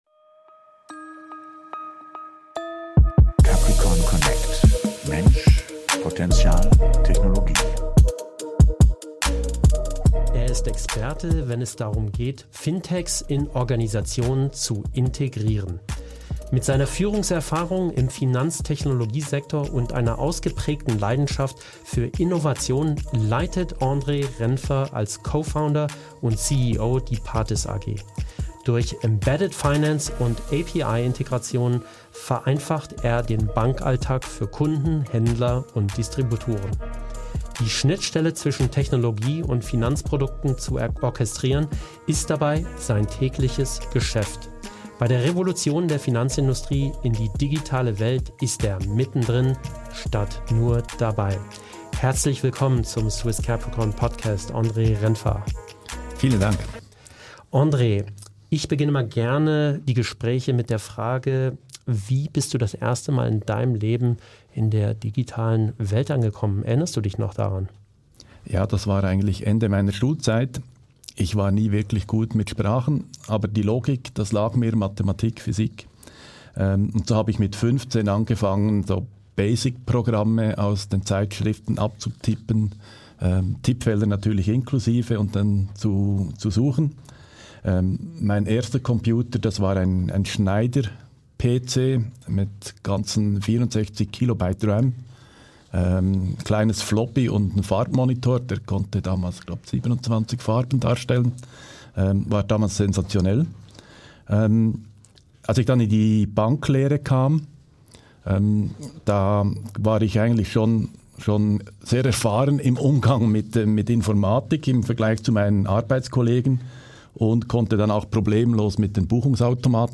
Themen der Episode: Was Embedded Finance wirklich bedeutet – und warum es mehr als nur ein Trend ist Welche Chancen und Risiken Banken jetzt erkennen müssen Warum Universalbanken verschwinden und spezialisierte Anbieter die Zukunft sind Die größten Herausforderungen im Recruiting & Leadership in Zeiten von KI Welche Jobs durch Künstliche Intelligenz ersetzt werden – und welche neu entstehen Ein spannendes Gespräch über Technologie, Innovation und den Wandel einer ganzen Branche.